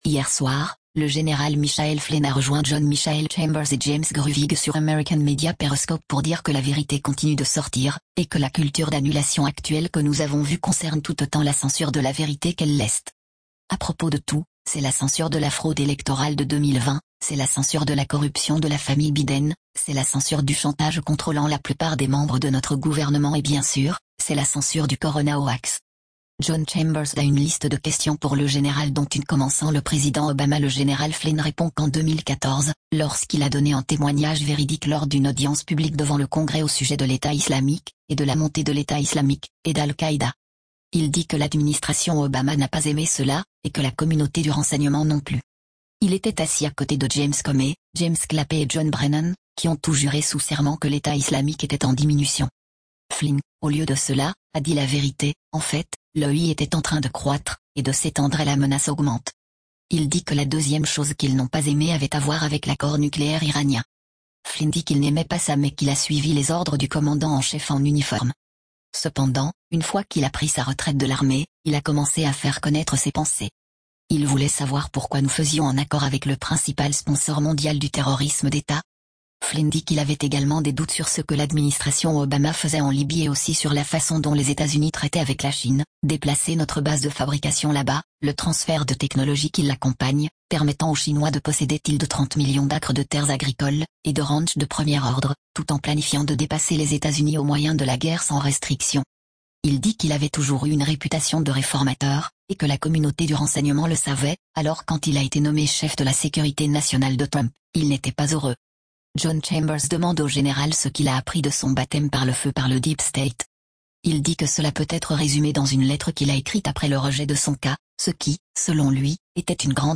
• Interview du Général Flynn traduite